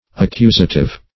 Accusative \Ac*cu"sa*tive\, n. (Gram.)